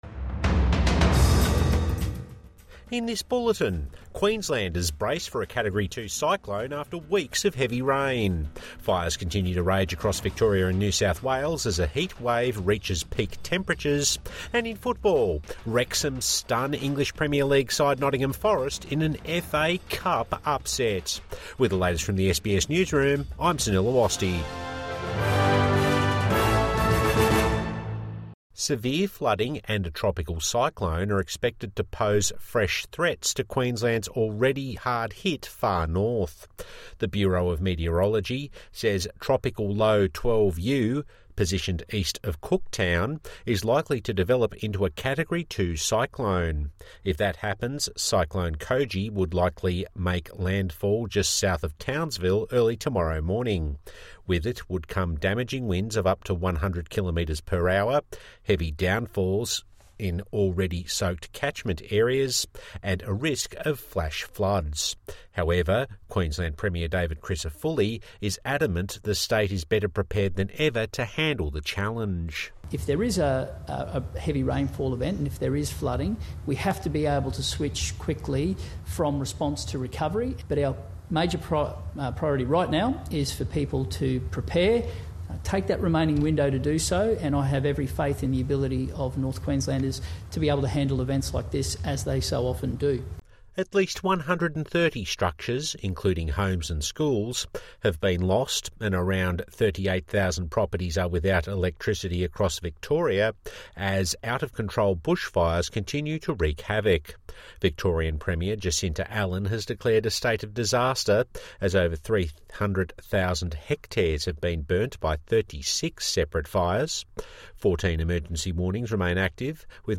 Evening News Bulletin